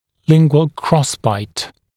[‘lɪŋgwəl ‘krɔsbaɪt][‘лингуэл ‘кросбайт]язычный (лингвальный) перекрестный прикус